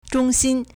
中心 zhōngxīn
zhong1xin1.mp3